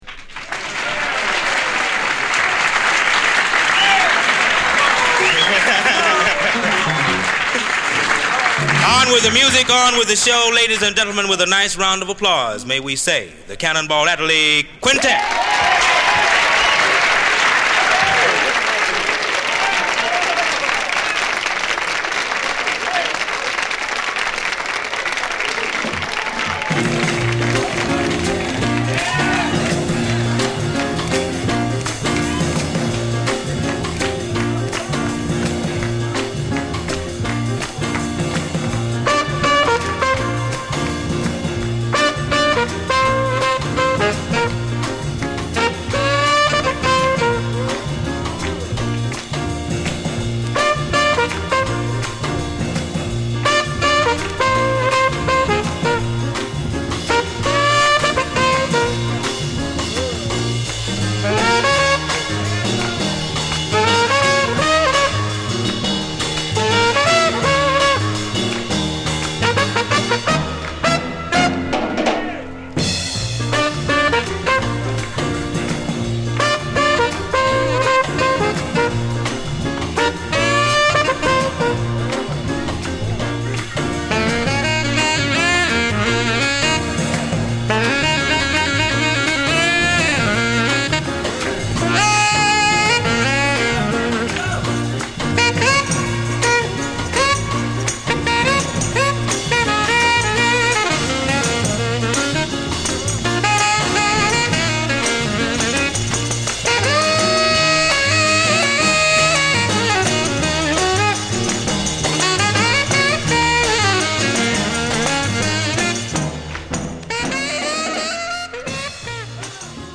本作ではユルめのアレンジ